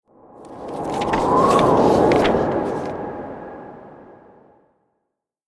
دانلود آهنگ باد 70 از افکت صوتی طبیعت و محیط
دانلود صدای باد 70 از ساعد نیوز با لینک مستقیم و کیفیت بالا
جلوه های صوتی